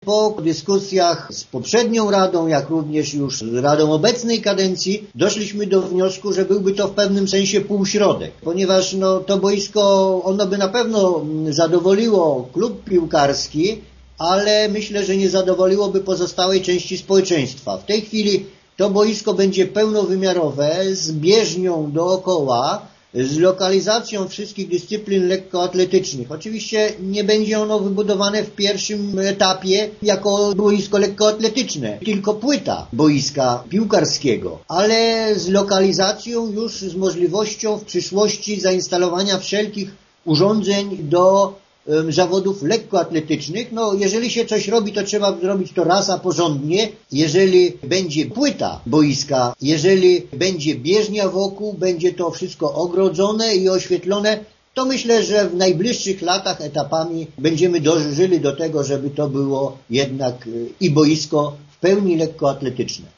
Franciszek Kwiecień przypomina, że pomysł budowy boiska nie jest nowy: